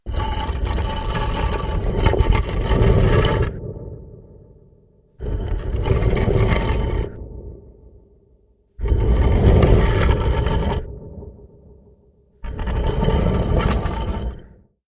На этой странице собраны загадочные звуки пирамид – от глухого эха в узких проходах до мистического гула древних сооружений.
Шум сдвига каменной двери в пирамиде